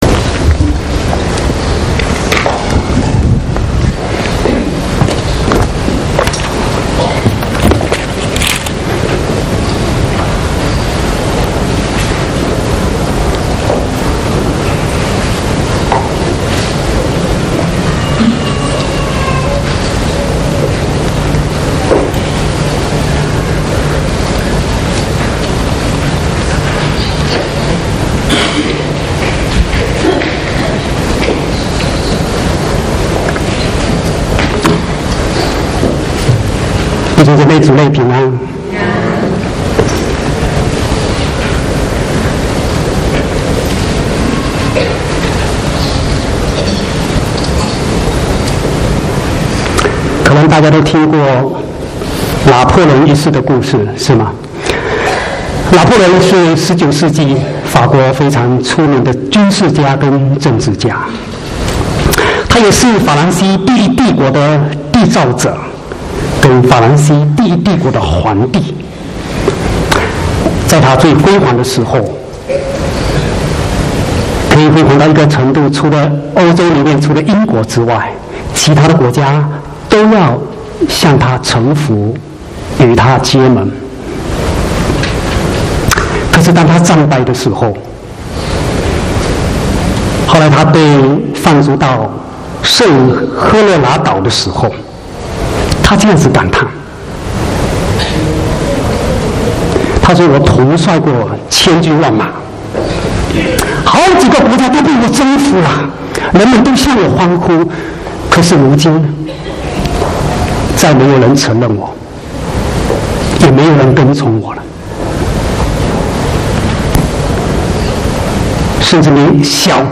25/9/2016國語堂講道